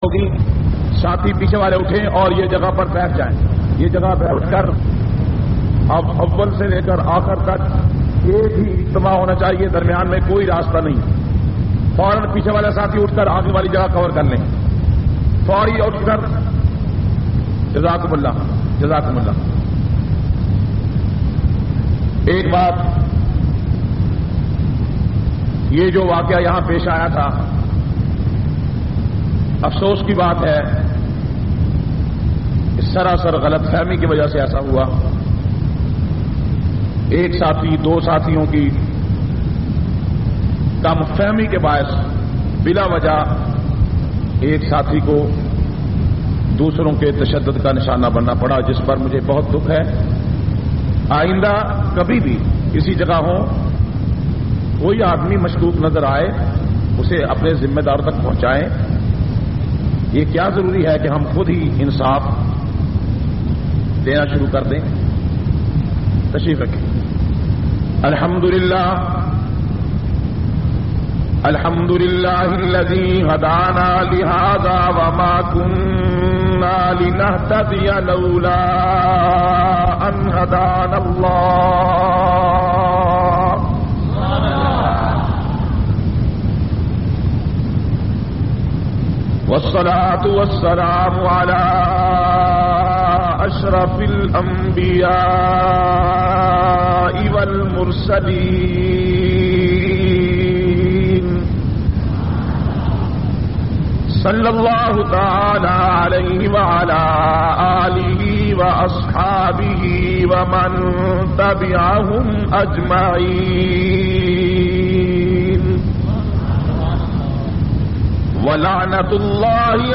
685- 7th International Conference-China Ground,Karachi.mp3